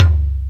Bassdrum-03.wav